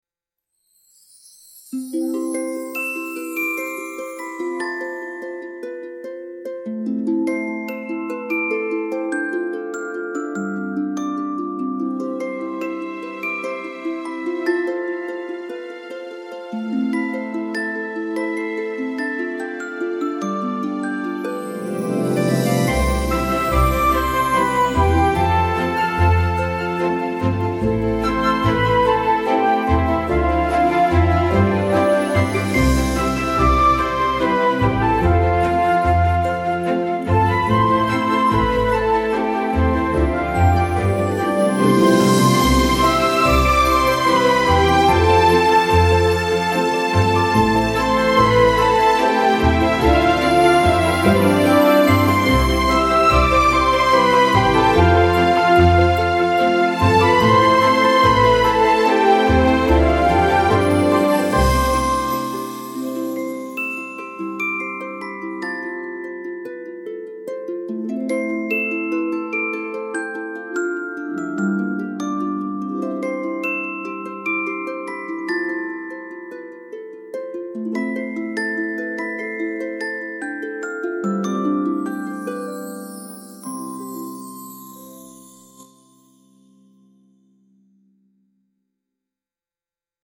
magical fairy tale music with twinkling glockenspiel and soft harps